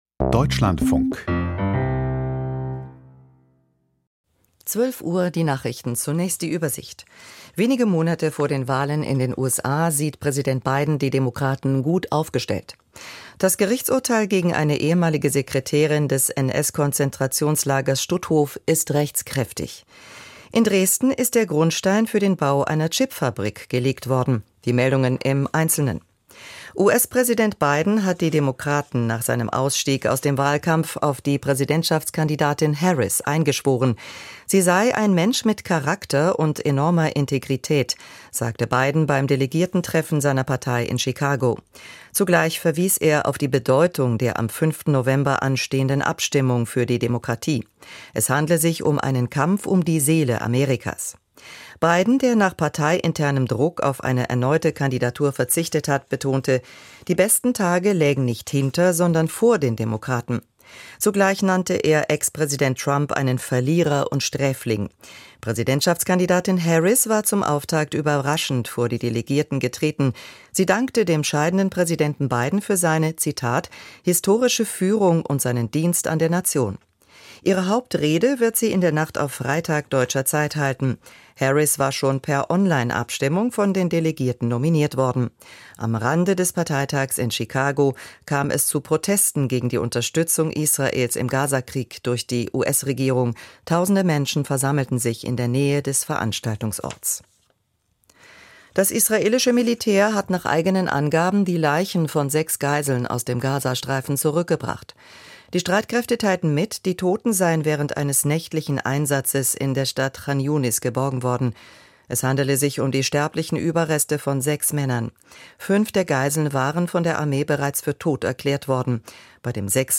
IT-Standort Ost: Interview